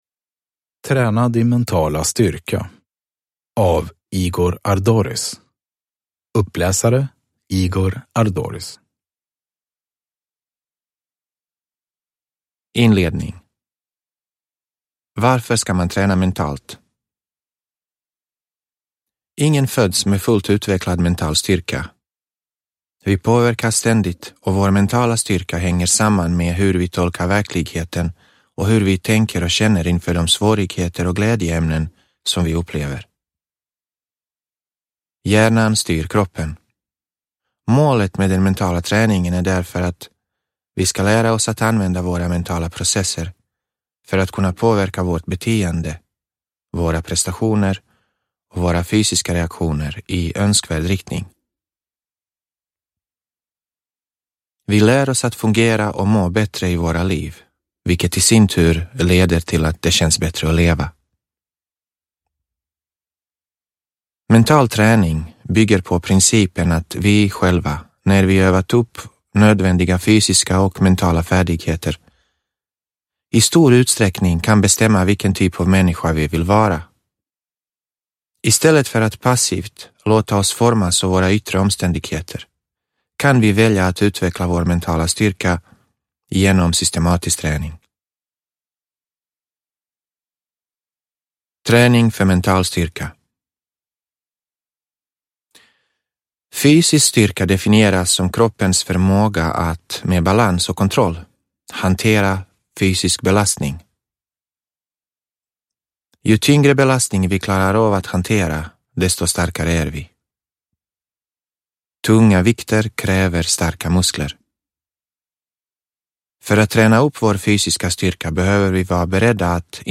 Träna din mentala styrka : En handledning – Ljudbok – Laddas ner